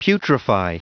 Prononciation du mot putrefy en anglais (fichier audio)
Prononciation du mot : putrefy